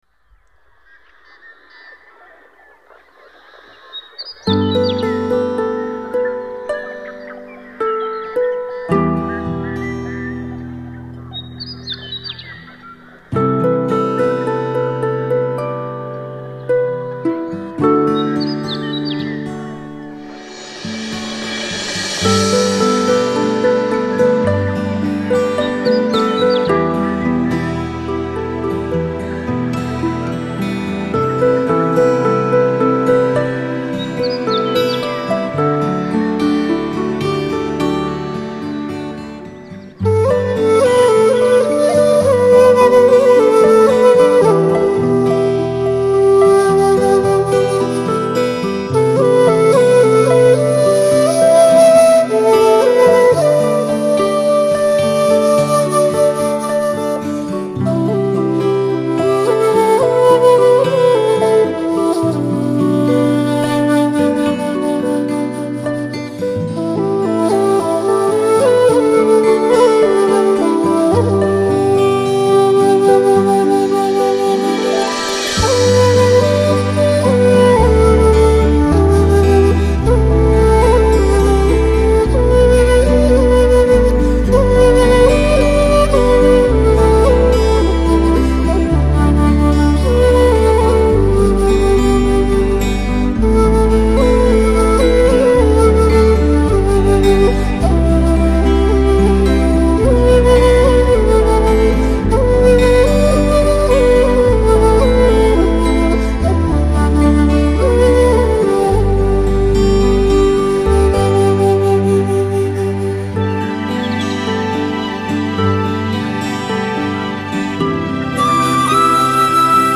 箫，是中国最古老的乐器之一，箫之声韵悠远，如泣如诉，别有一番超凡脱俗、清淡高远、悠扬古雅的意境。
尤其，箫曲中的清虚淡远、细腻典雅，最能表现远离尘世、隐居山林、寄情山水的悠恬处得。
笛、箫、电子吹管、巴乌